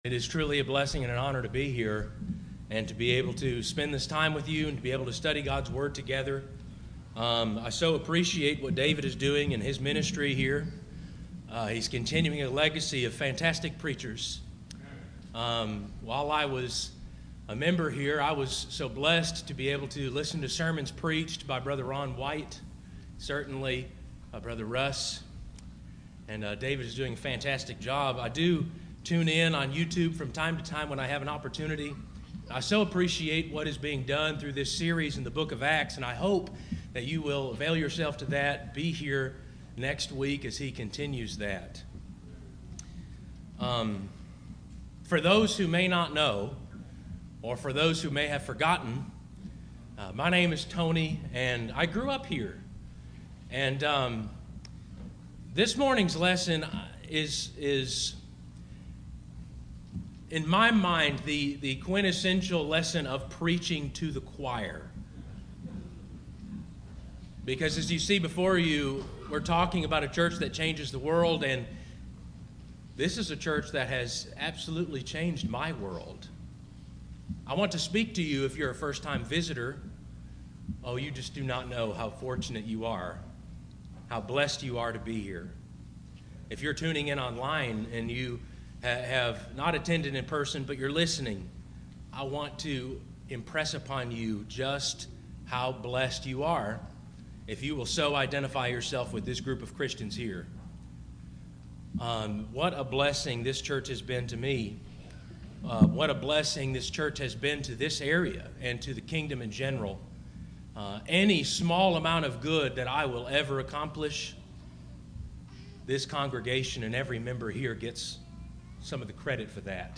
Tagged with sermon